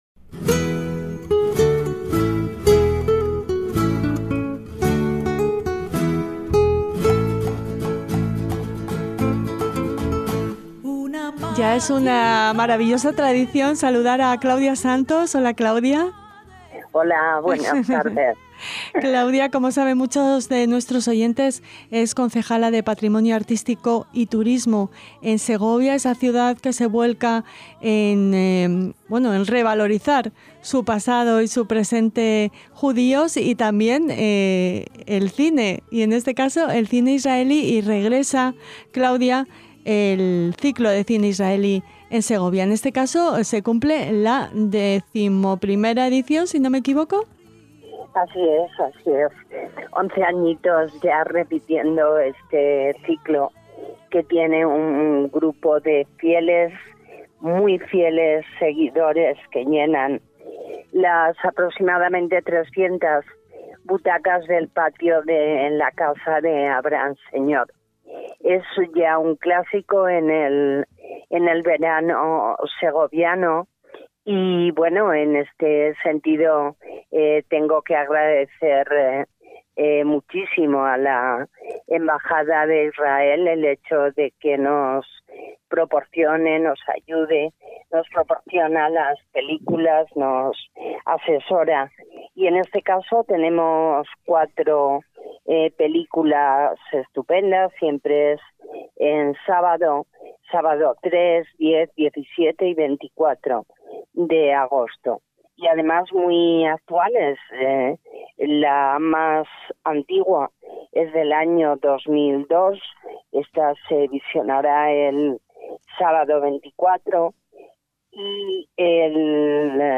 En lo que ya es una deliciosa tradición veraniega entrevistamos a la concejala de Patrimonio Artístico y Turismo del Ayuntamiento de Segovia, Claudia Santos, quién nos traslada al patio de la Casa de Abraham Seneor para invitarnos a ocupar una de las 300 butacas disponibles para disfrutar del mejor cine israelí y de las tapas del Fogón sefardí maridadas especialmente con cada película.